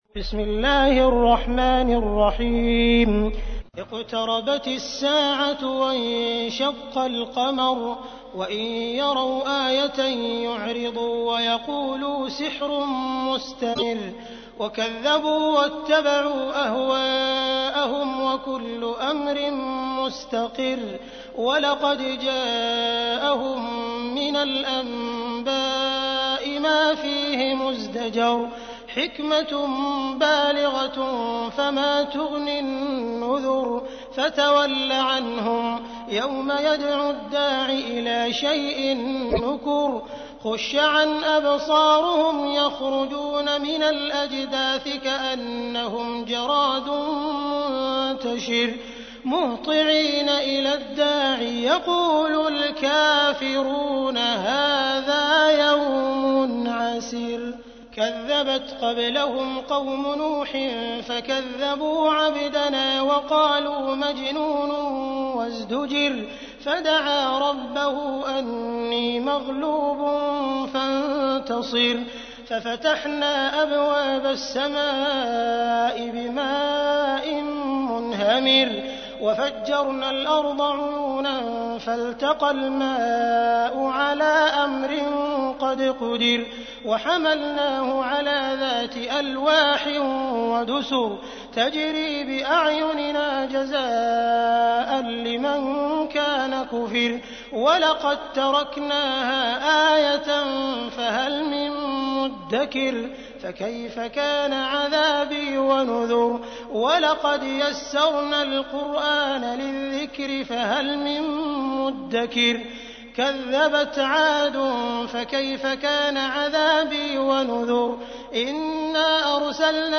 تحميل : 54. سورة القمر / القارئ عبد الرحمن السديس / القرآن الكريم / موقع يا حسين